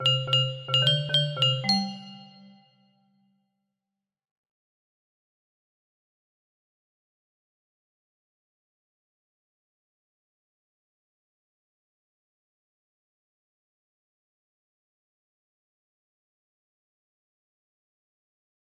star theme music box melody